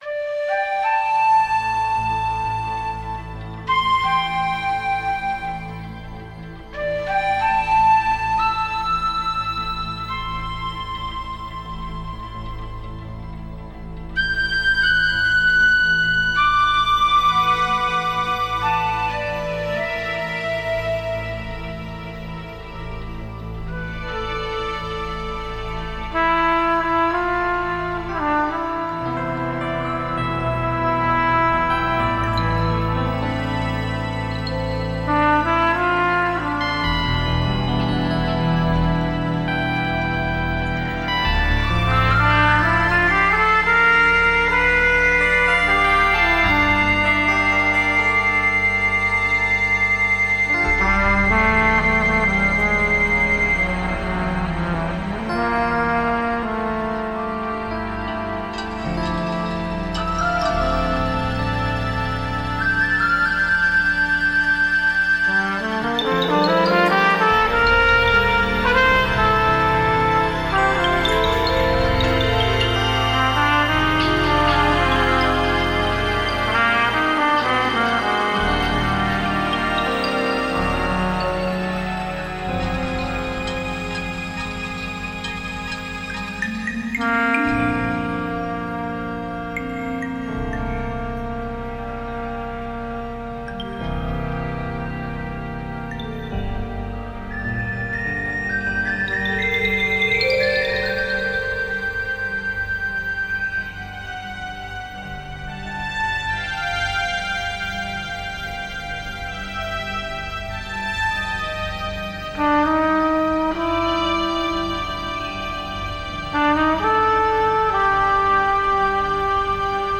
Had to sell my Z trumpet to pay bills. Said goodbye at the last moment in a unprepared mess of a song. All improvised 1st takes, mistakes and all.